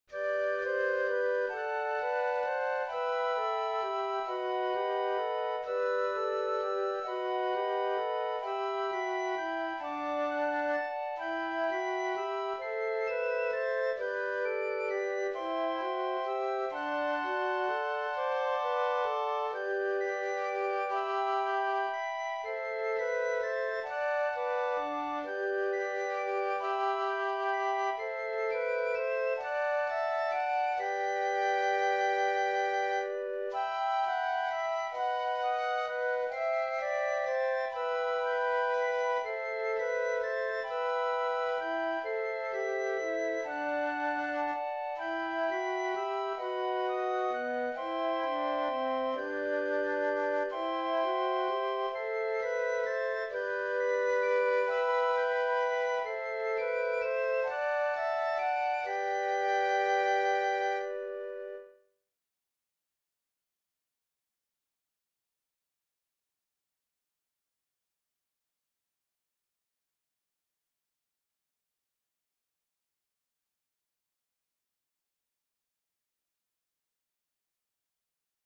Tuulessa-kulkea-saan-huilut.mp3